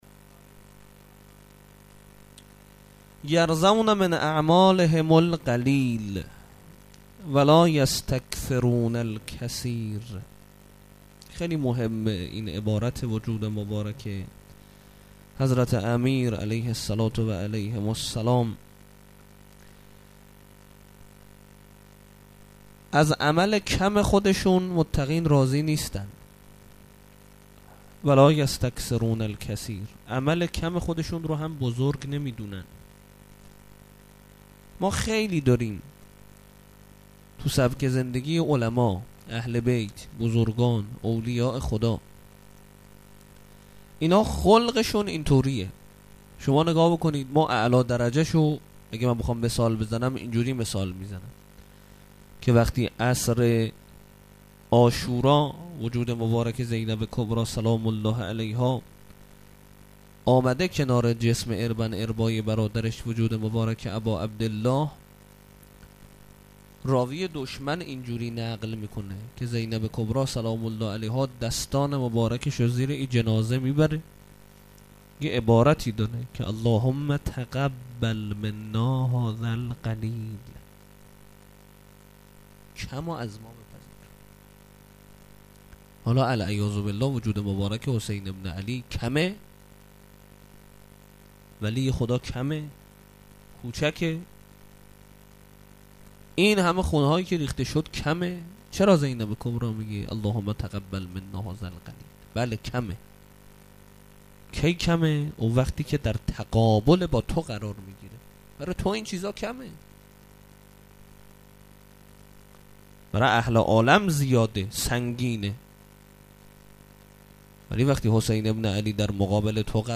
سخنرانی-1.mp3